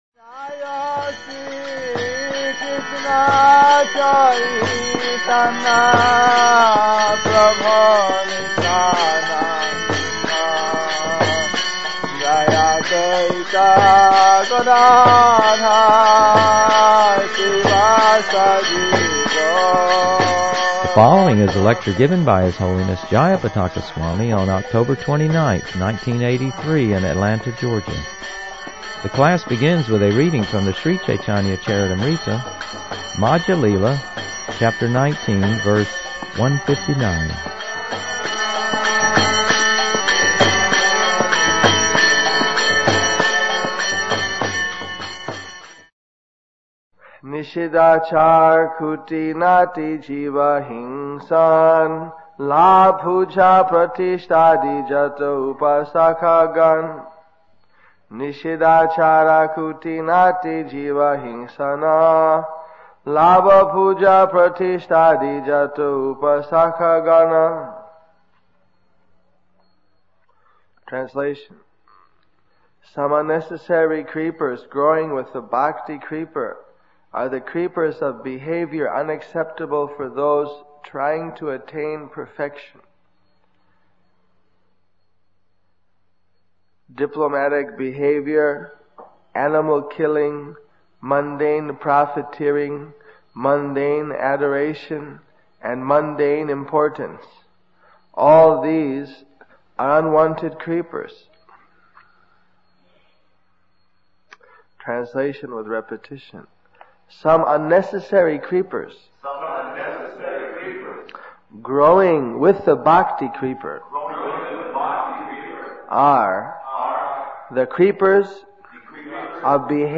Lectures
The class begins with a reading from the Sri Caitanya-Caritamrita, Madhya-lila, Chapter 19, Verse 159.